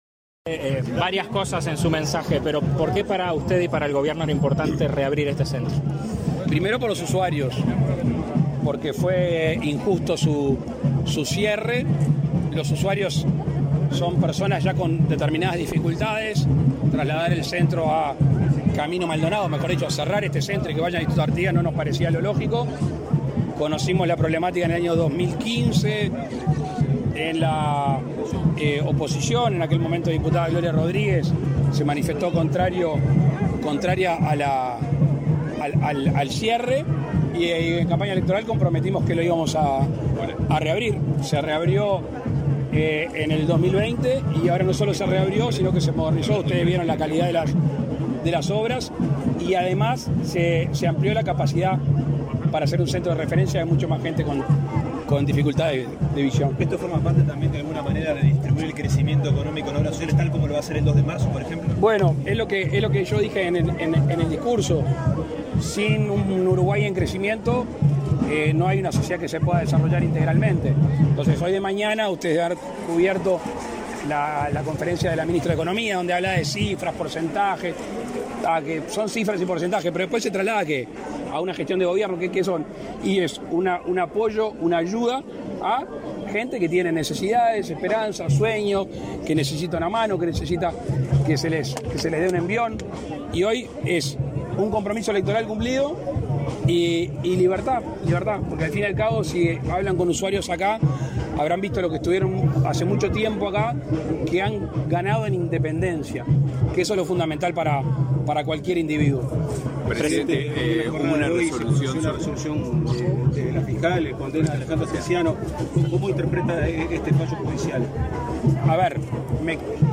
Declaraciones a la prensa del presidente de la República, Luis Lacalle Pou
Tras participar en la inauguración de obras de remodelación y acondicionamiento del centro de rehabilitación para personas con discapacidad visual Tiburcio Cachón, realizadas por el Ministerio de Desarrollo Social (Mides) y el Ministerio de Transporte y Obras Públicas (MTOP), este 15 de febrero, el presidente Luis Lacalle Pou realizó declaraciones a la prensa.